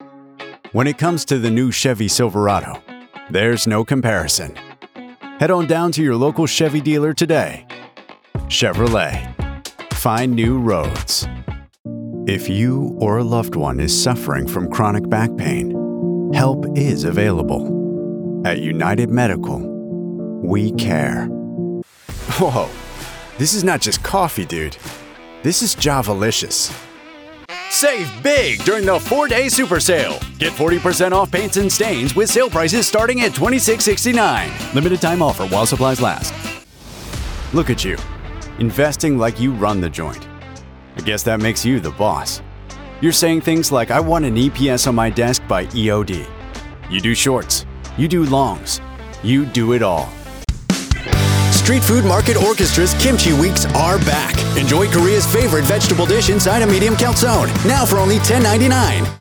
Commercial Reel US English